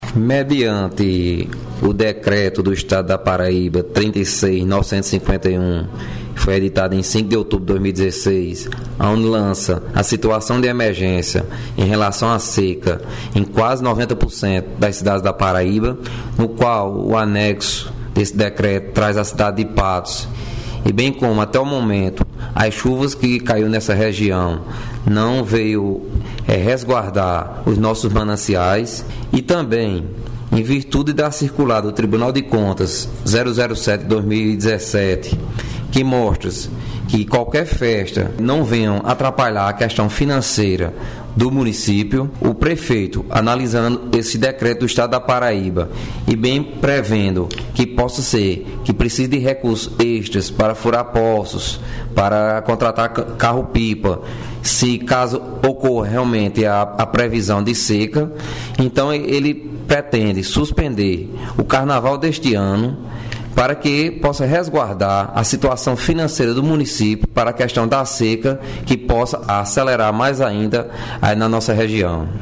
Fala do Procurador Geral do Município de Patos, Phillipe Palmeira –